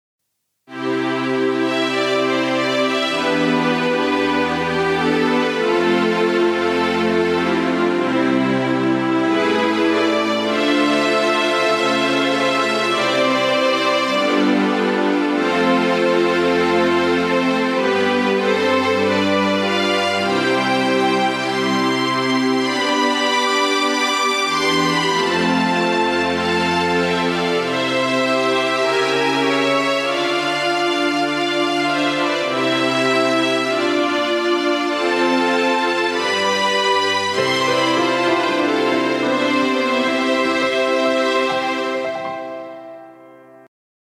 הפעם נסיתי משהו קצת יותר חלומי… רוק מעניין.mp3
את הסנר עצמו פחות אהבתי.